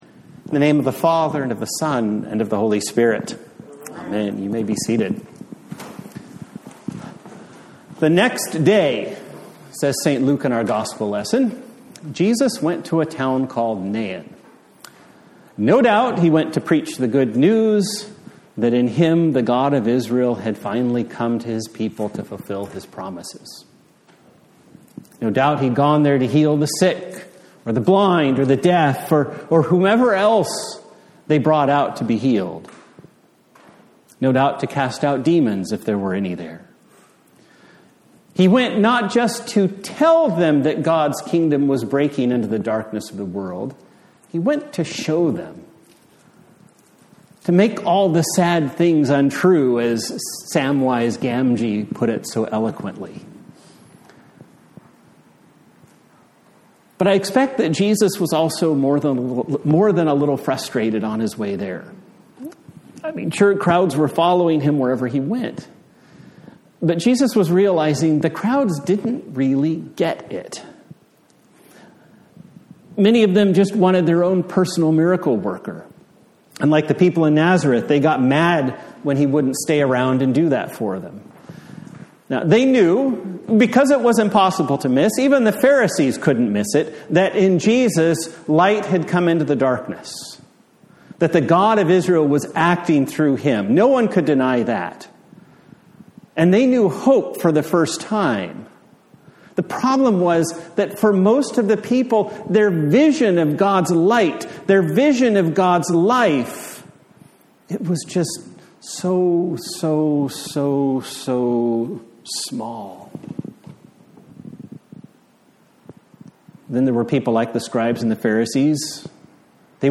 A Sermon for the Sixteenth Sunday after Trinity
Service Type: Sunday Morning